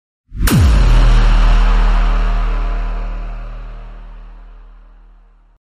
Звуки шока
В коллекции представлены различные варианты: резкие скримеры, звуки удивления, напряженные моменты из фильмов и игр.